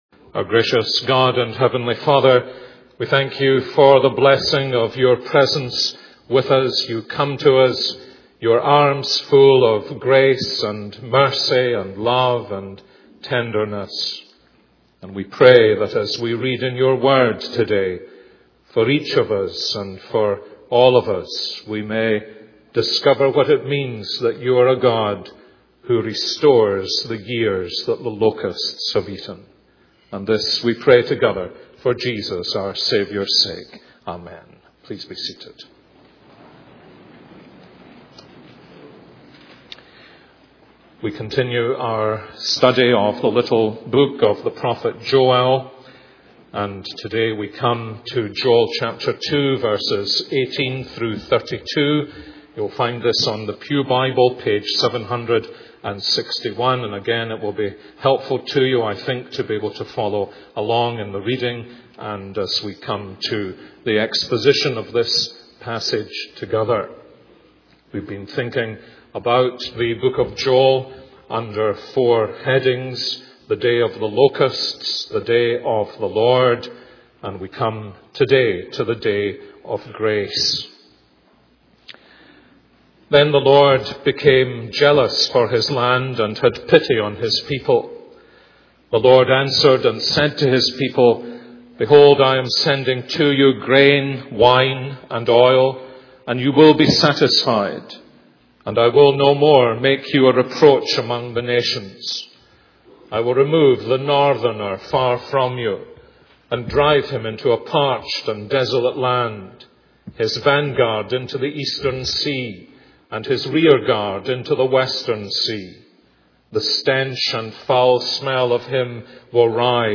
This is a sermon on Joel 2:18-32.